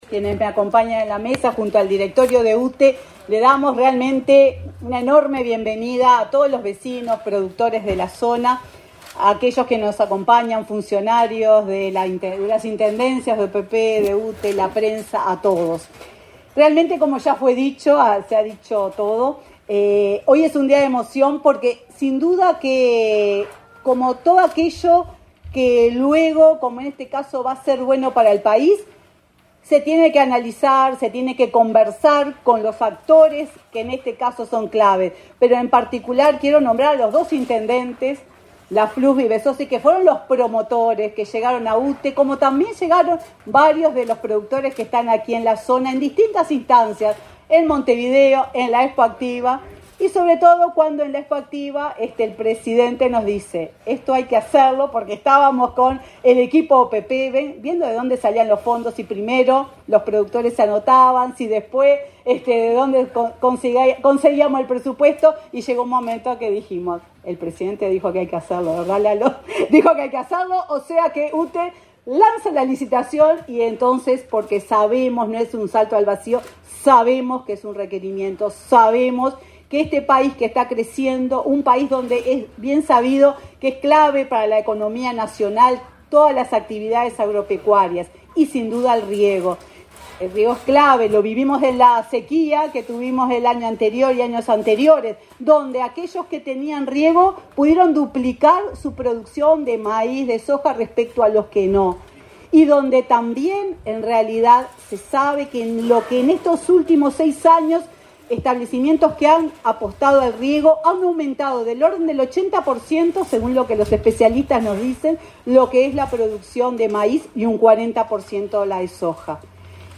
Palabras de la presidenta de UTE, Silvia Emaldi
Palabras de la presidenta de UTE, Silvia Emaldi 23/12/2024 Compartir Facebook X Copiar enlace WhatsApp LinkedIn Con la presencia del presidente de la República, Luis Lacalle Pou, fue inaugurada, este 23 de diciembre, una subestación de 30/15 kilovatios (kV) sobre la ruta n.º14 y una línea de subtransmisión de 60 kV que la conecta con Palmar. En el evento, disertó la presidenta de la UTE, Silvia Emaldi.